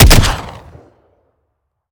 weap_hdromeo_sup_plr_01.ogg